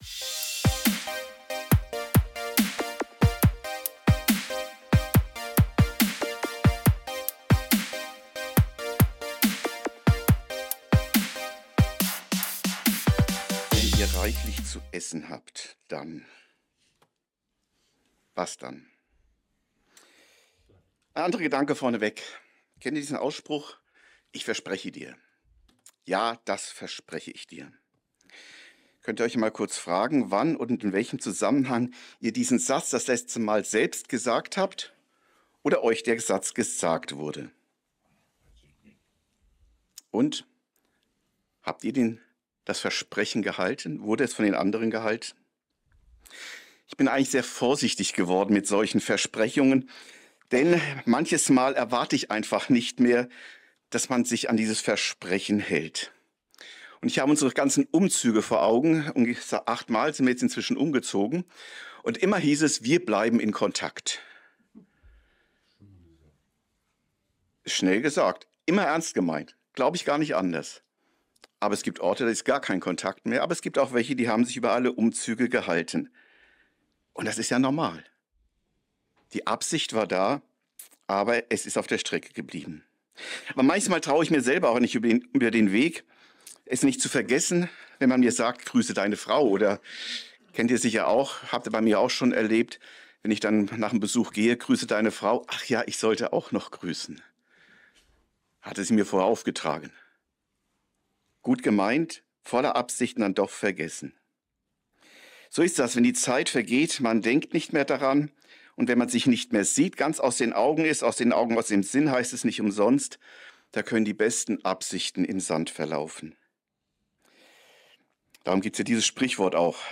... und wenn du satt geworden bist ~ Predigten u. Andachten (Live und Studioaufnahmen ERF) Podcast